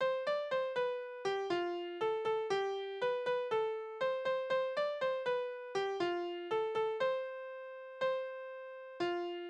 Kindertänze: Mariechen auf dem Stein
Tonart: F-Dur
Taktart: 4/4
Tonumfang: große Sexte
Besetzung: vokal